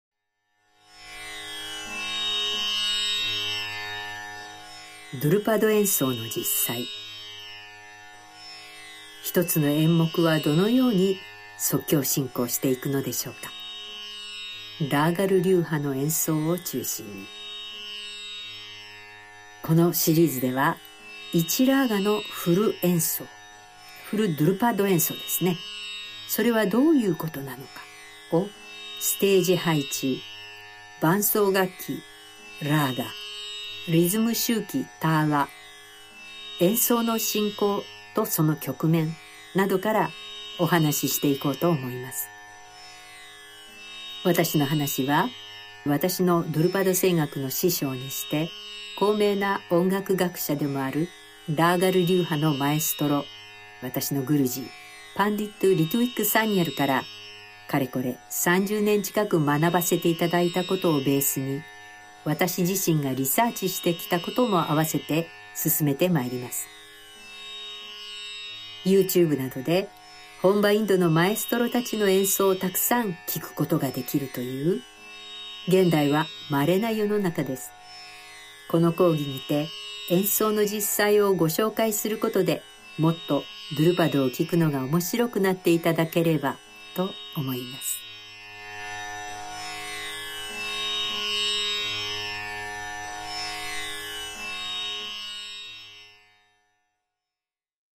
★この講義について (Trailer / Japanese, 1:37)
BGM: Antique Tanpura G# made in Kolkata